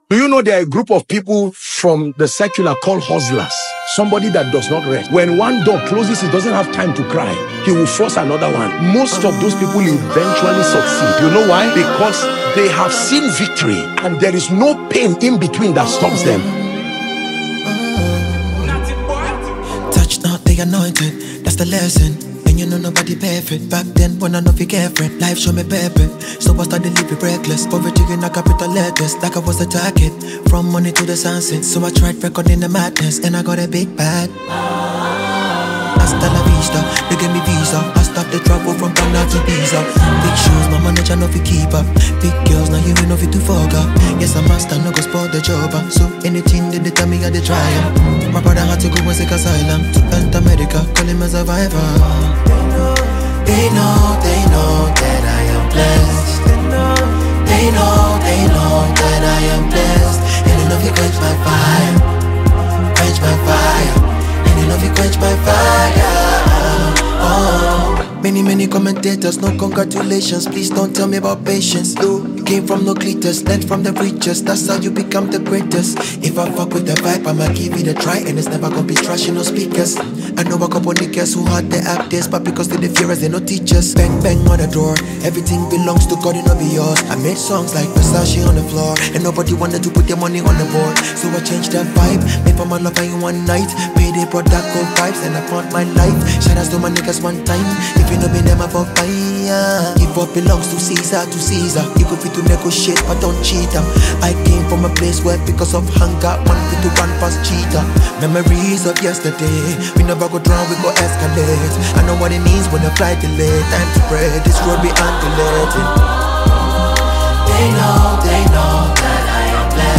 All-time high-rated Afrosound singer